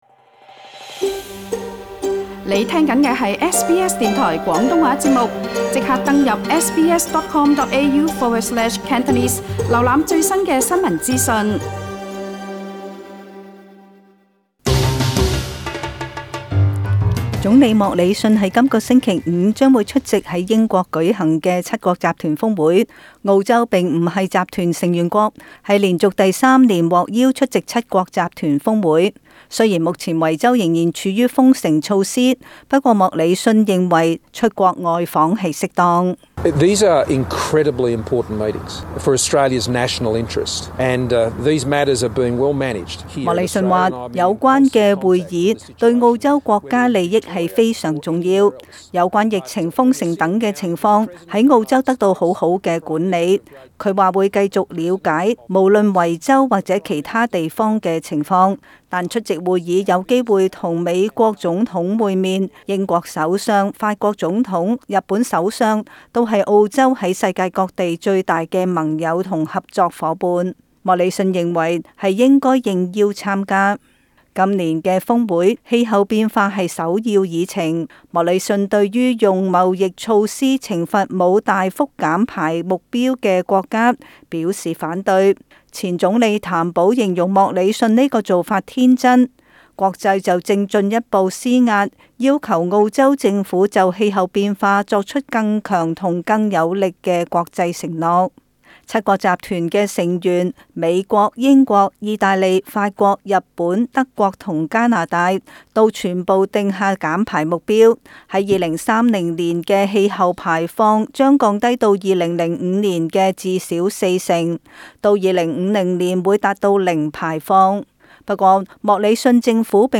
【時事報道】